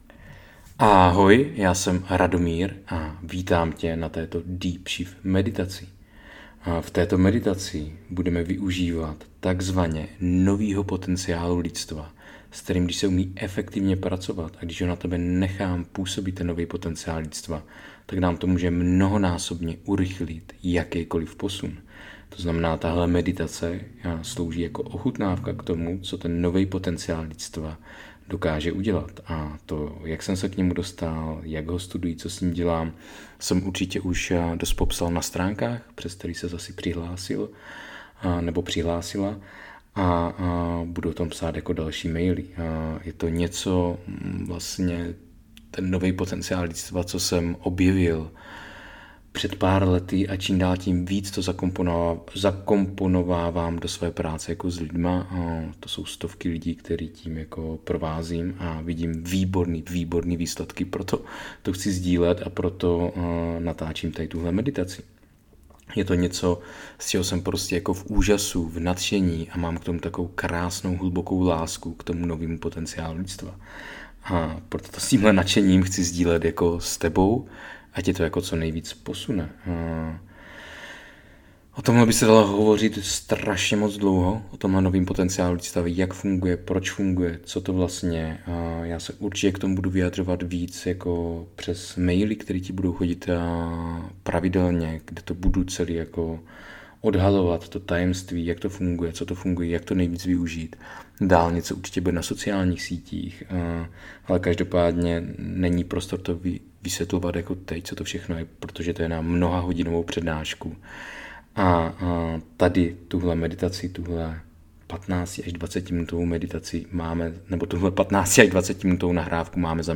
DeepShift meditace: Nastolení vnitřní pohody DeepShift meditace: Nastolení vnitřní pohody Tato DeepShift meditace tě provede jedinečným procesem aktivace tvé přirozené vnitřní pohody. V úvodní části ti vysvětlím klíčové principy a složky vnitřní pohody, a poté tě provedu samotnou meditací, kde nový potenciál lidstva působí přímo na tvé emoční podvědomí. Meditace pro vnitřní pohodu (19 minut) V první části této meditace ti vysvětlím, jak funguje nový potenciál lidstva a jak jeho síla dokáže transformovat tvé emoční podvědomí.